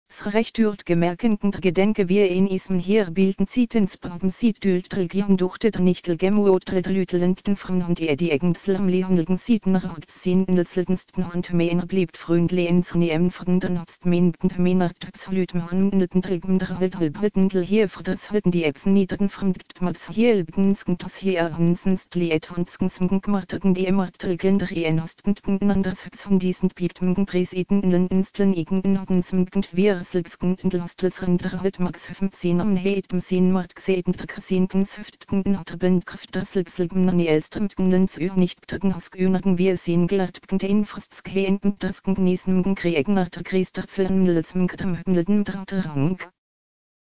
Diesmal ist es nun wirklich, wirklich wahr: Was aus unseren Lautsprechern ertönt, ist zwar noch nicht perfekt, aber zum ersten Mal klingt es tatsächlich nach Mittelhochdeutsch!
Wir könnten uns allerdings deutlich besser auf diese Probleme konzentrieren, wenn Hedda nicht ganz so atemlos durch den Text hasten würde.